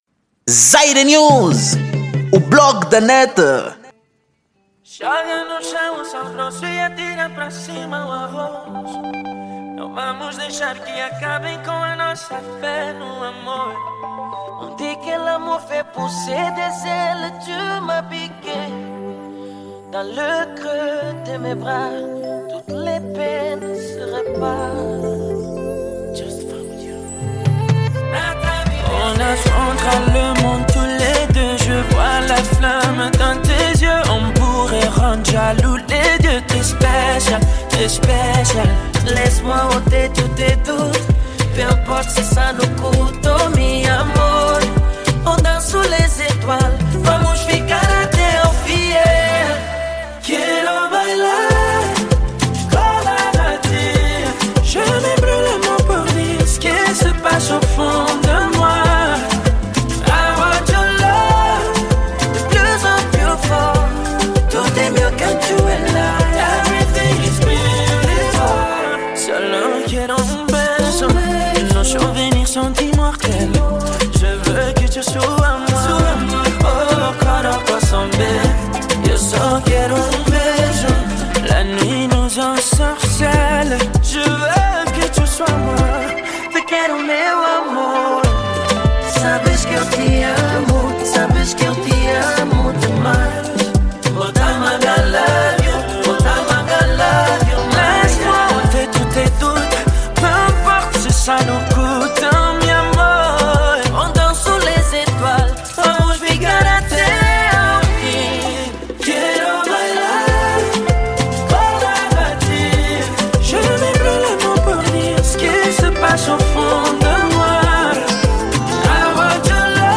Gênero: Dance Hall